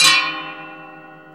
METAL HIT 4.wav